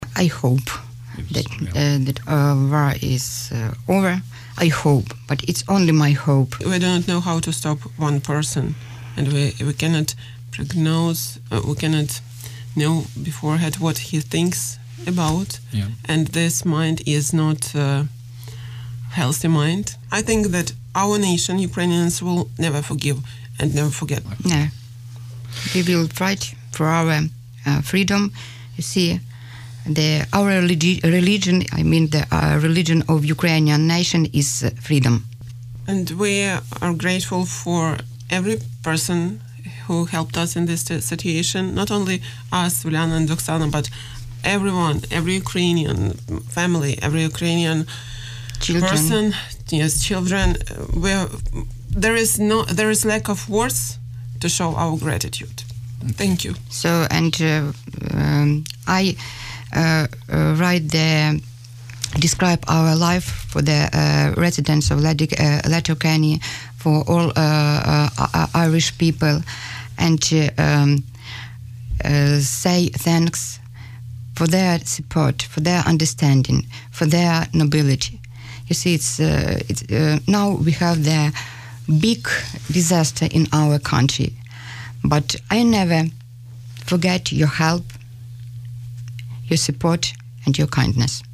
Two Ukrainian women have expressed their gratitude to the people of Donegal and Ireland for the support in the crisis.